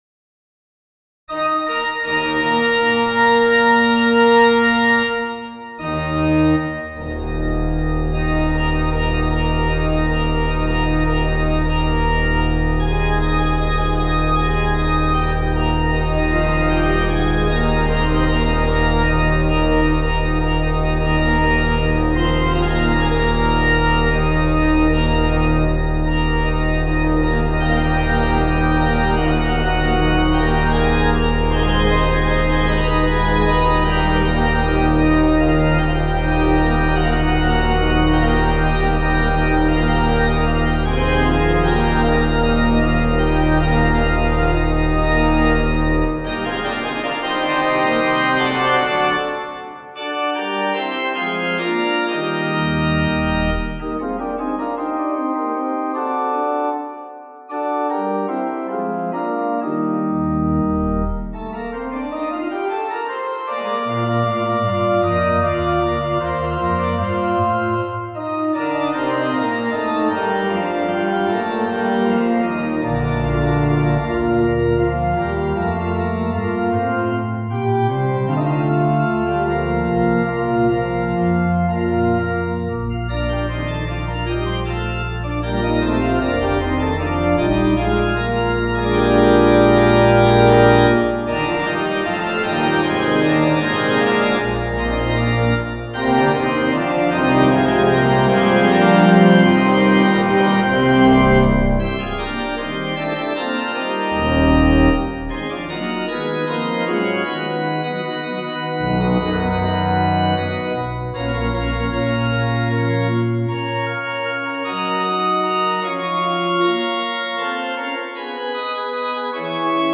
for organ
The notion of a subject of mostly repeated fifths came to mind, and from thence to this, rooted on the tonic and subdominant. For this, a third within any portion of the subject becomes very effective, domains for answers then include the dominant major and submediant minor, as well as touching some more distant harmonic domains.
8 pages, circa 5' 00" an MP3 demo is here: